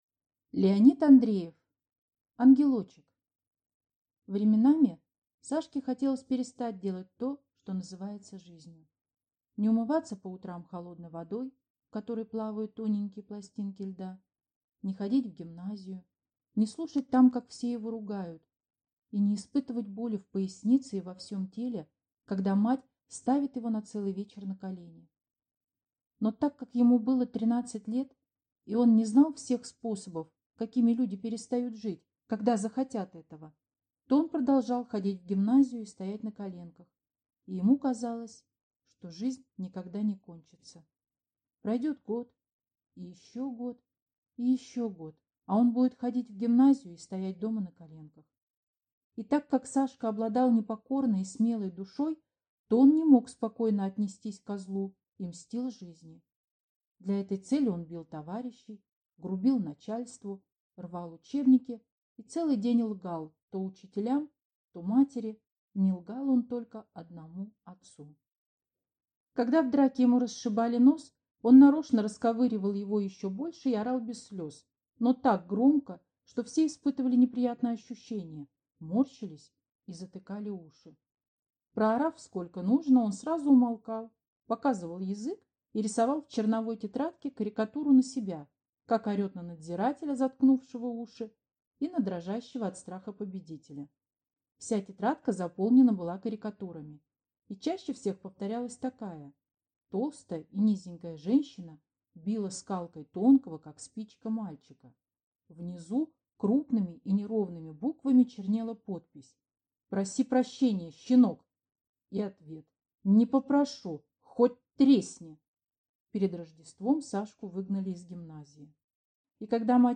Аудиокнига Ангелочек | Библиотека аудиокниг